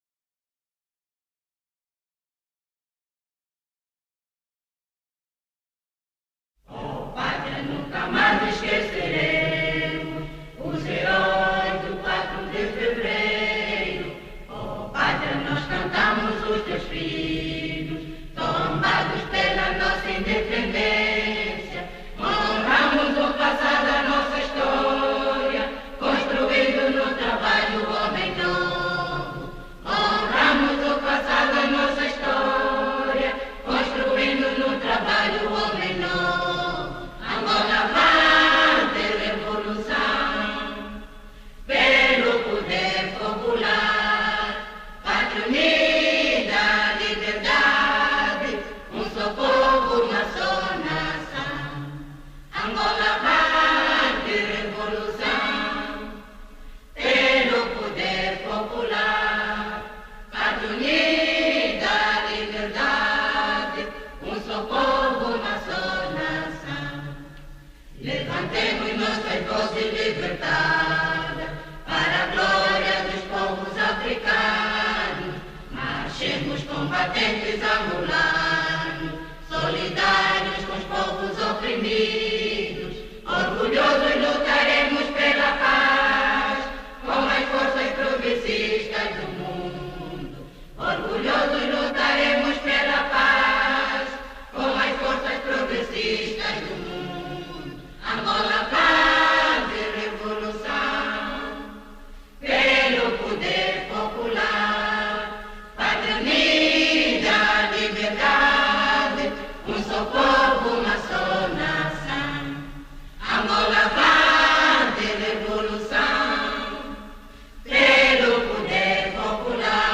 а капелла